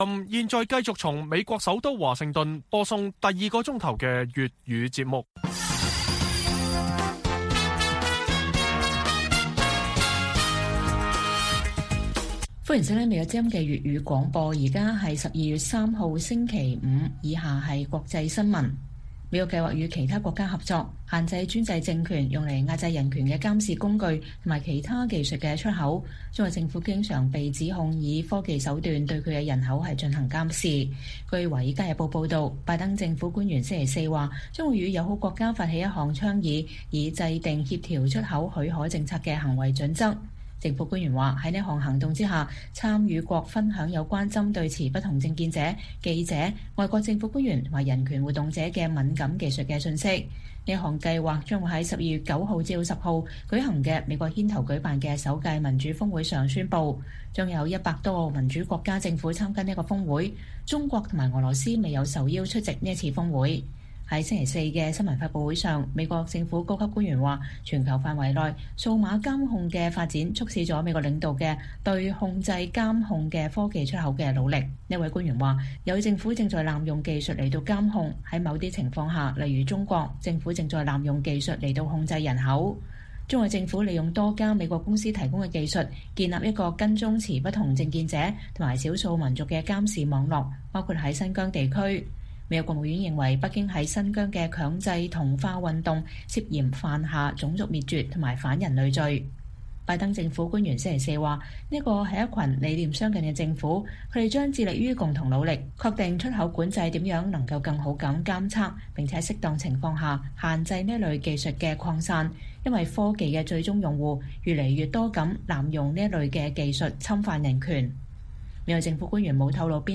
粵語新聞 晚上10-11點:限制獨裁政權以科技壓制人權 美國帶頭推動全球技術出口管制